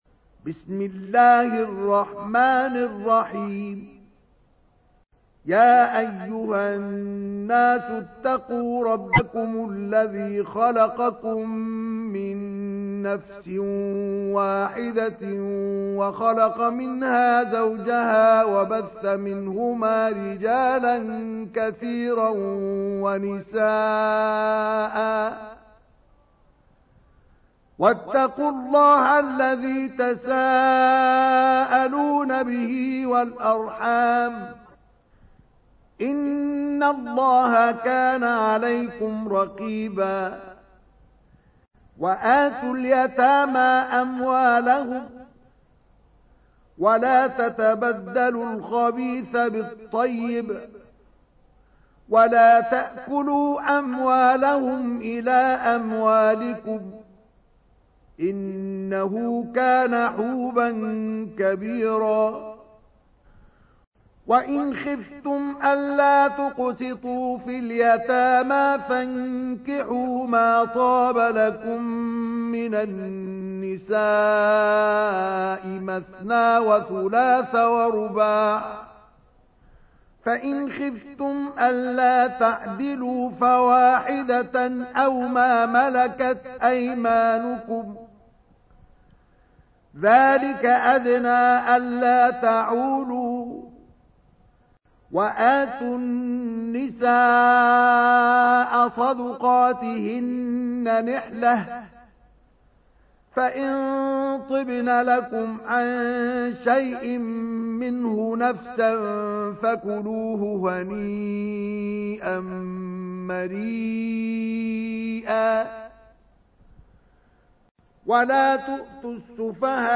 تحميل : 4. سورة النساء / القارئ مصطفى اسماعيل / القرآن الكريم / موقع يا حسين